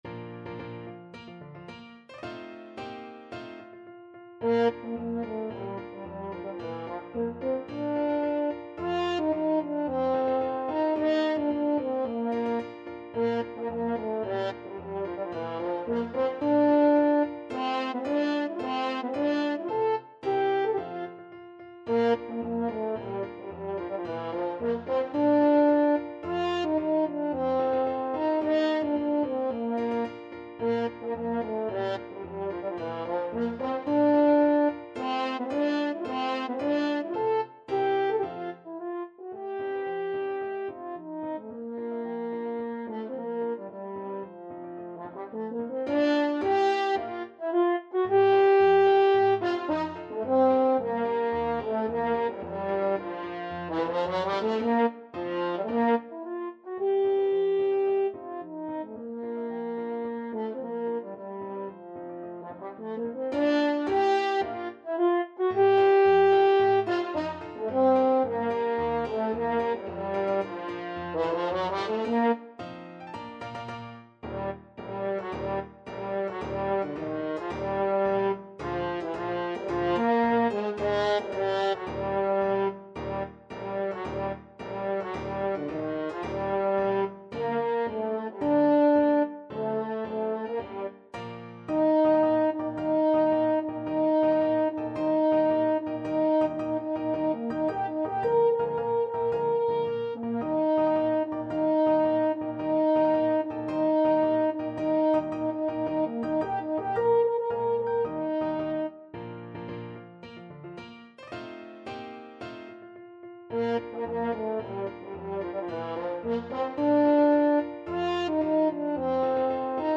March =c.110
2/2 (View more 2/2 Music)
Classical (View more Classical French Horn Music)